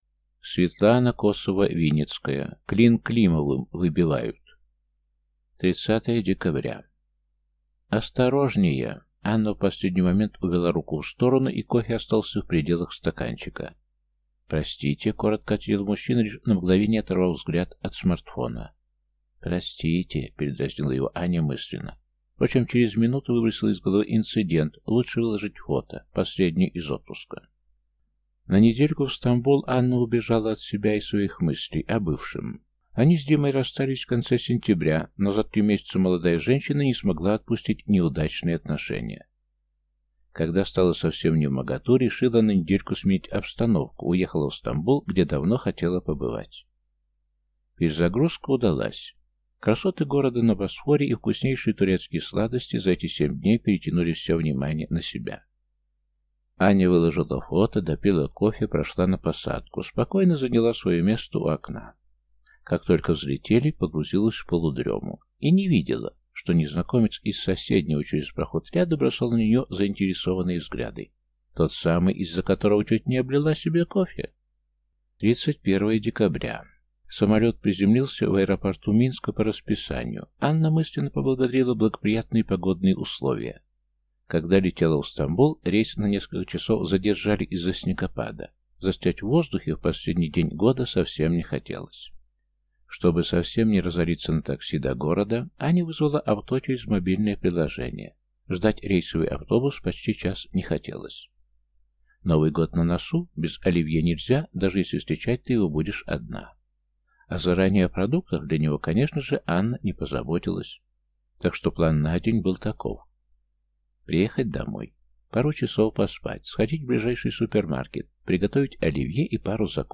Аудиокнига Клин Климовым выбивают | Библиотека аудиокниг